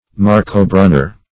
Marcobrunner \Mar`co*brun"ner\, n.